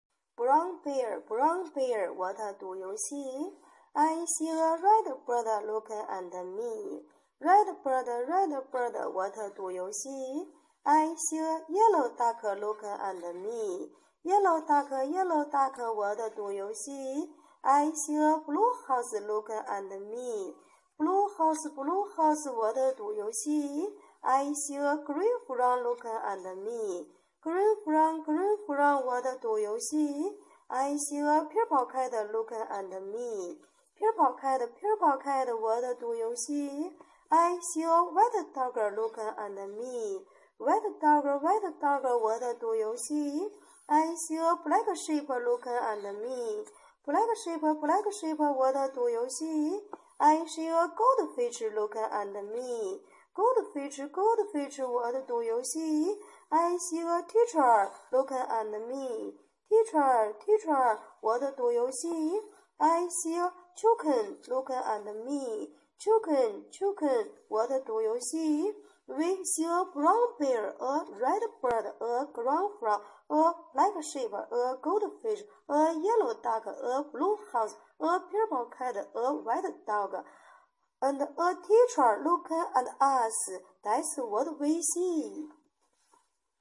阿姨讲英语.mp3